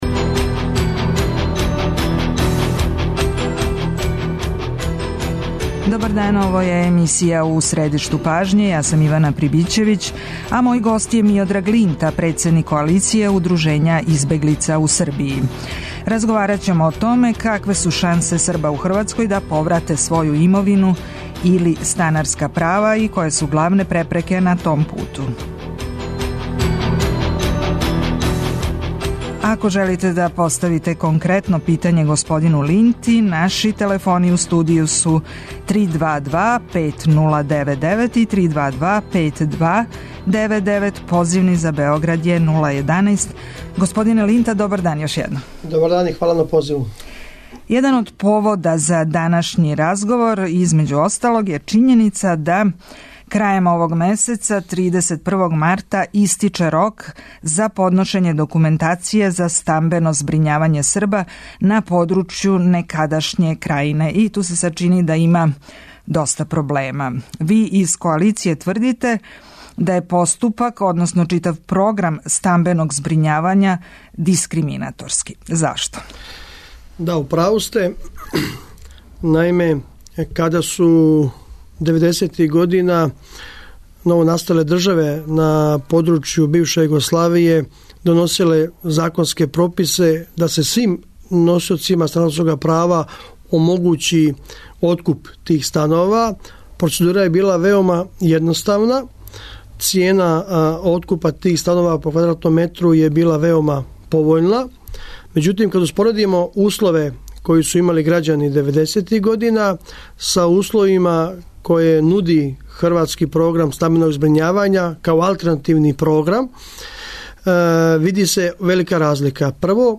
Како решити основне проблеме Срба у Хрватској и прогнаних у Србији? Шта избегличка удружења очекују од нове власти у Србији? Гост емисије биће Миодраг Линта, председник Коалиције избегличких удружења.